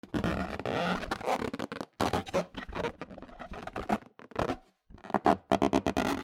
ゴム風船
『ブブ』